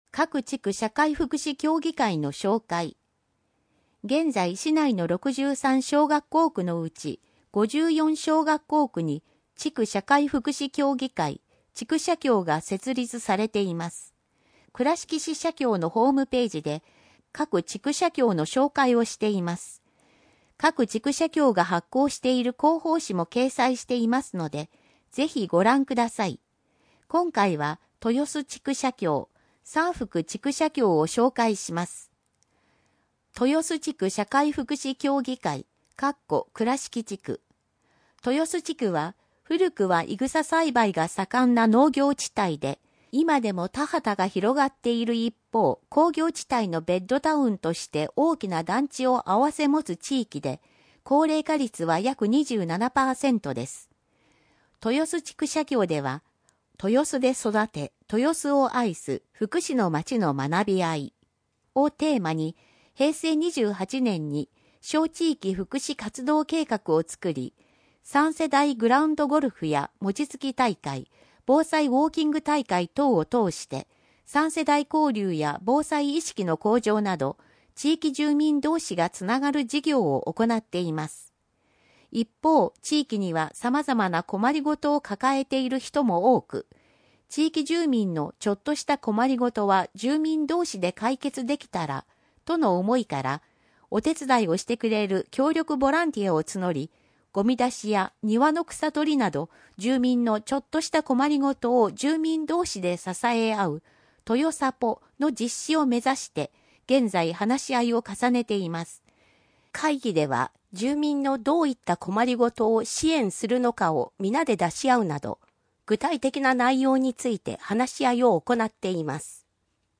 くらしき社協だより第77号 音訳版